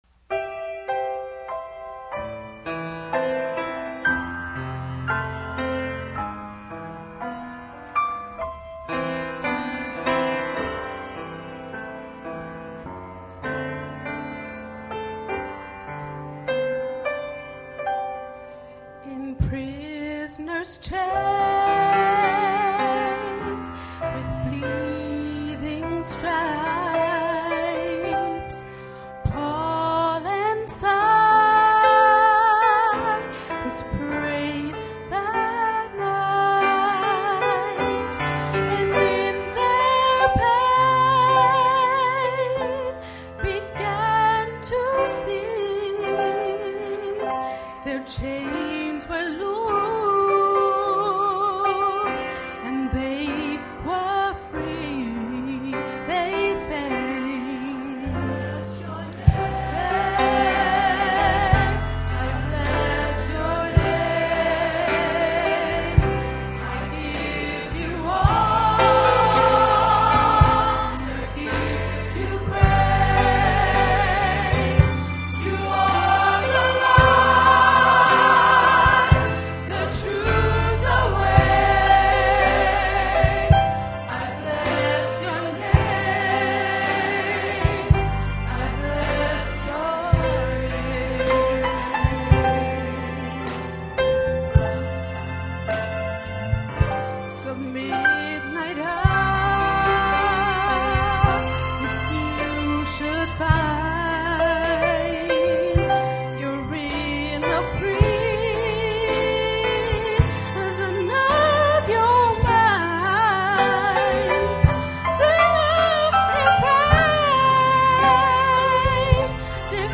The Swarthmore College Alumni Gospel Choir celebrates its 40th anniversary this year.
Amen” from their campus concert this spring.